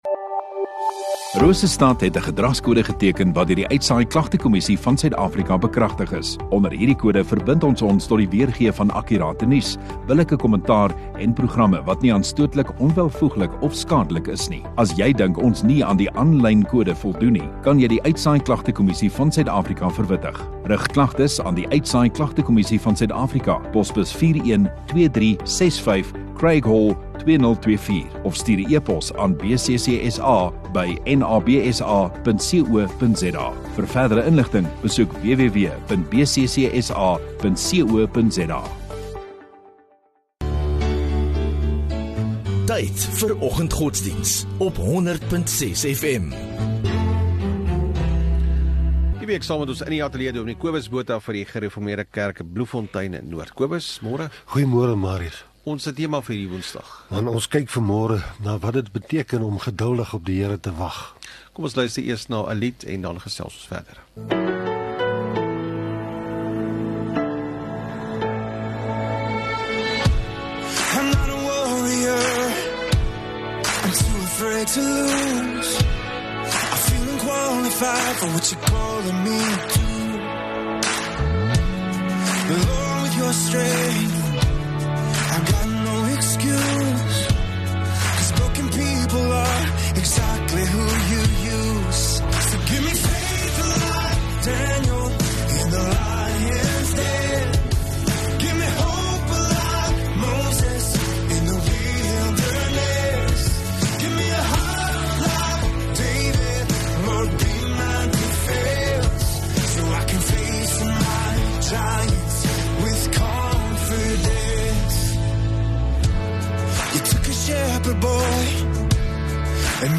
2 Oct Woensdag oggenddiens